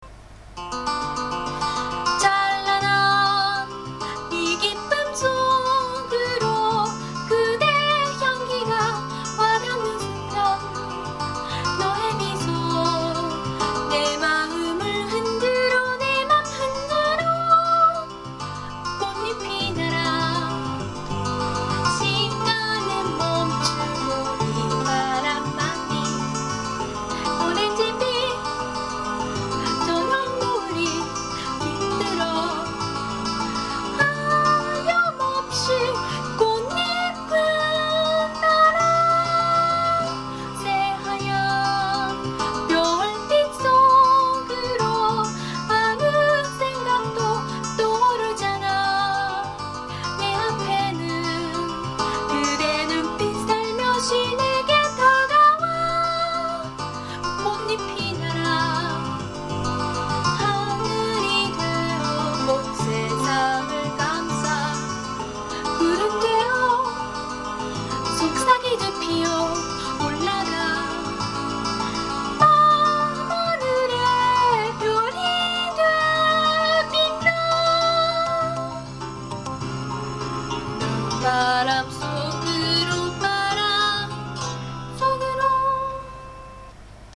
いずれもｍｐ３ファイルです．泣かせます！発音もいいですよ！
音楽（歌入り）